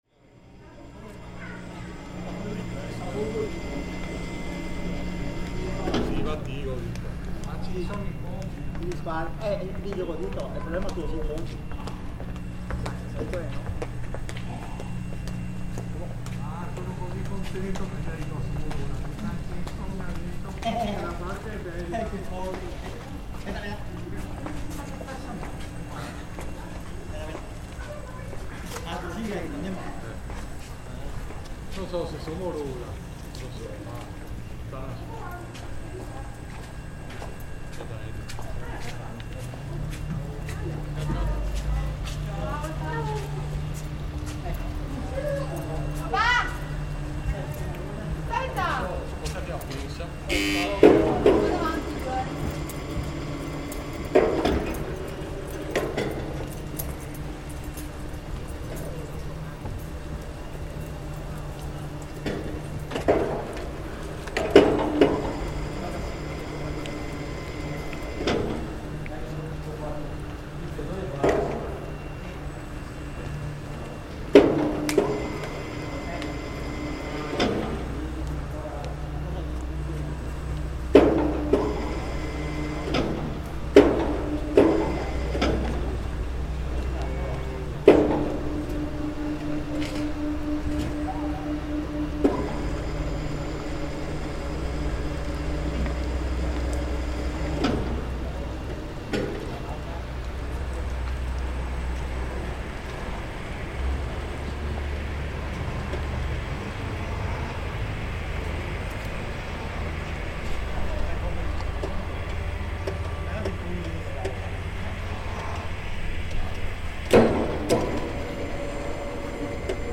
On the island of San Pietro di Castello in Venice, on an otherwise tranquil and still weekday afternoon, we hear a boat being lowered into the water by one of Venice's typical boat cranes. The operator spins the boat around and zooms off - and at the close of the recording, the slosh of the wash against the boats opposite us almost sounds like a grand applause celebrating the achievements of the boat workers.